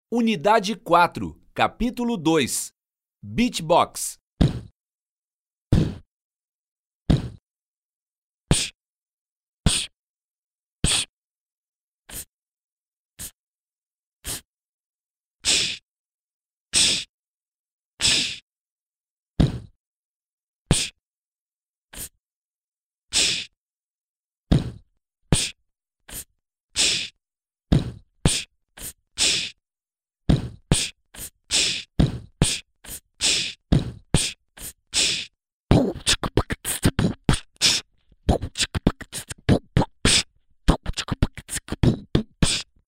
Beatbox
p_PRart_un04au_beatbox.mp3